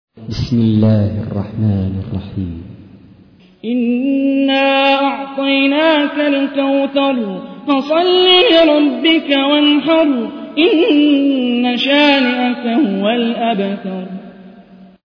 تحميل : 108. سورة الكوثر / القارئ هاني الرفاعي / القرآن الكريم / موقع يا حسين